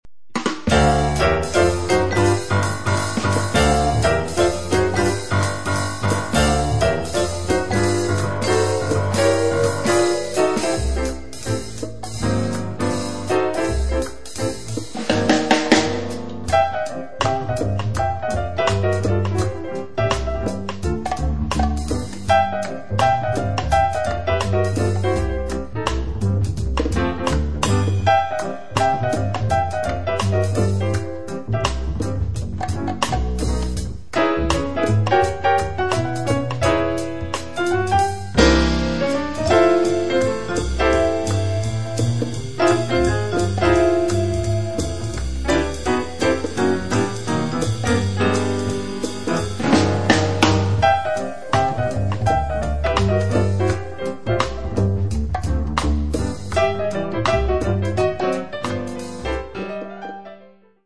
piano, vibes
bass
drums
bongos & congas
violin
ukulele
offrono un modello di vivace gradevolezza